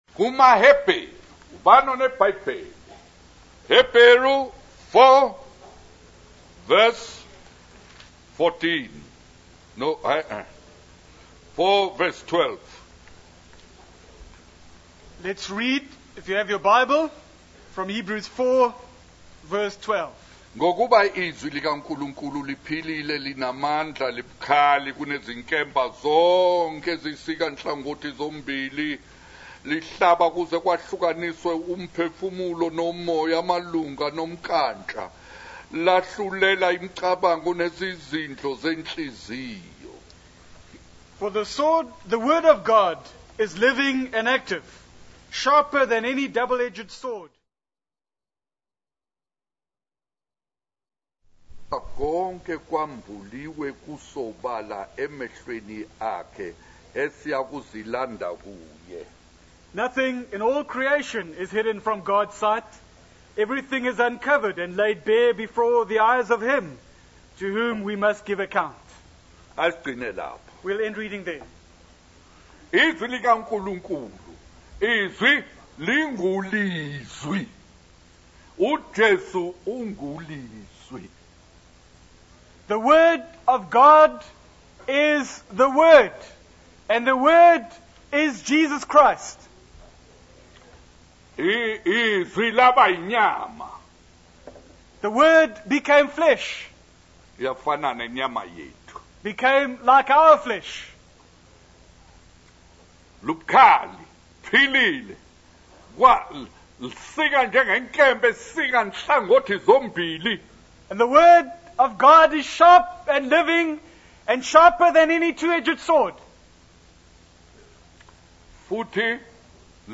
In this sermon, the preacher discusses the consequences of hiding secrets and the importance of exposing everything to the light of God. He shares three stories of individuals who kept secrets and faced negative outcomes.